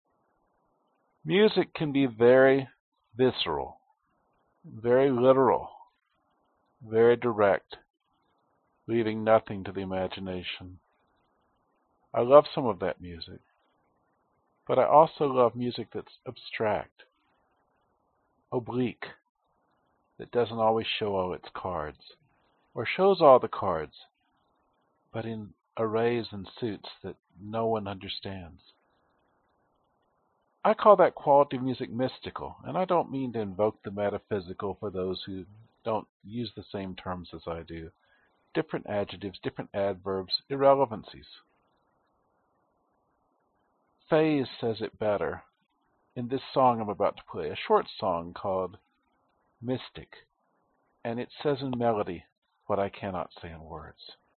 Tag: 保险杠 惯性 神秘 缟玛瑙